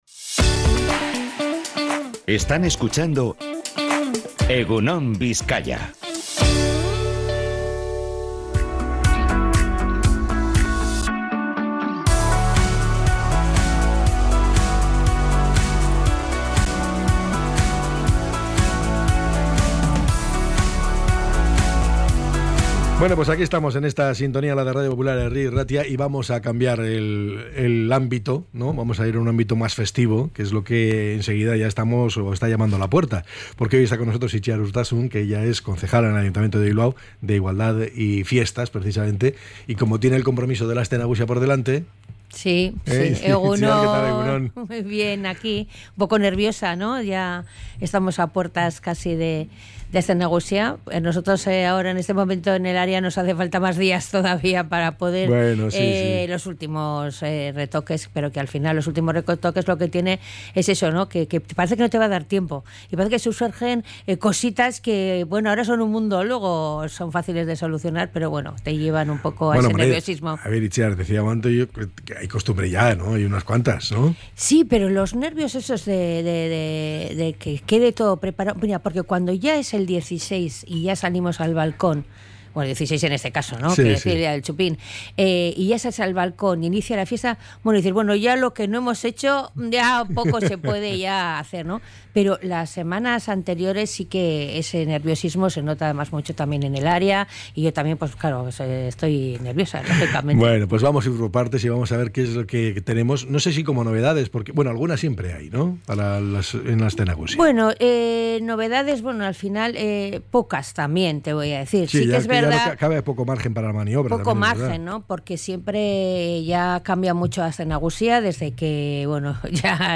La Concejala de Fiestas explica todos los detalles sobre la organización de Aste Nagusia y las iniciativas de igualdad
ENTREV-ITZIAR-URTASUN.mp3